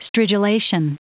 Prononciation du mot stridulation en anglais (fichier audio)
Prononciation du mot : stridulation